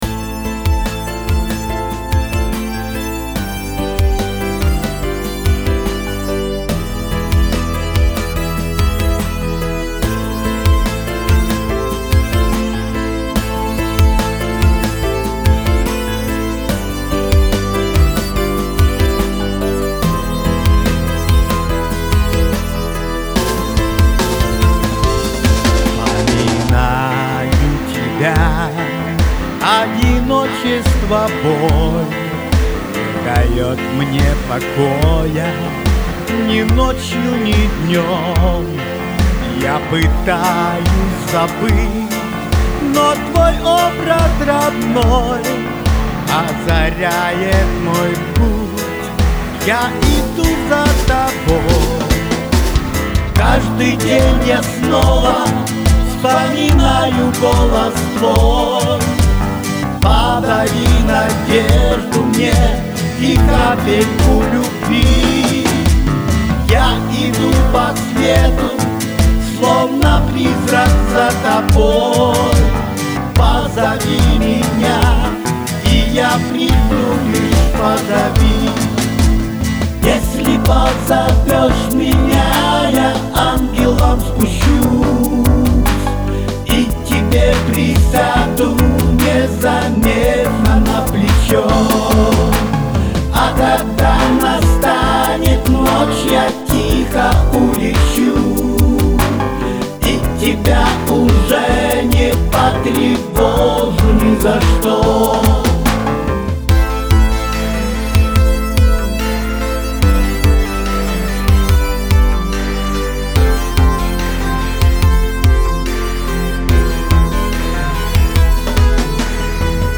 Ни одна из песен не доведена до конца потому , что обе они не подвергались тщательному сведению и мастерингу .